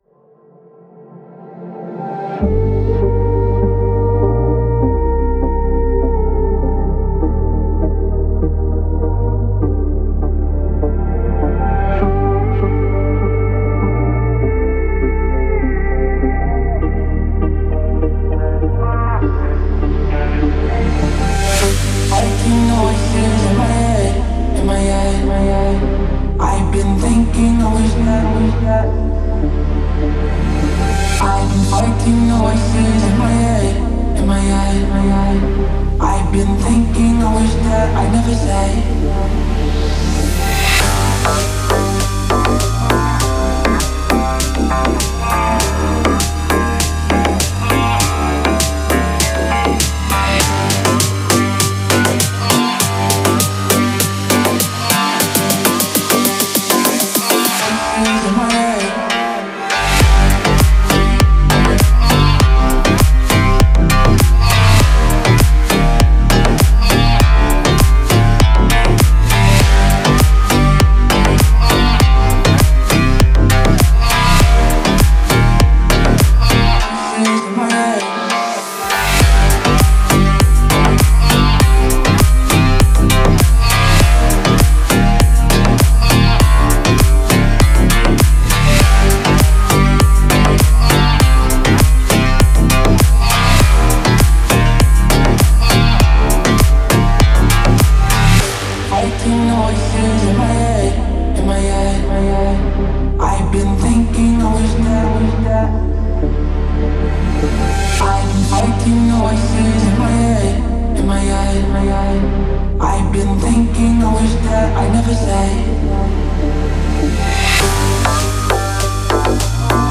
который сочетает в себе элементы поп и инди-рока.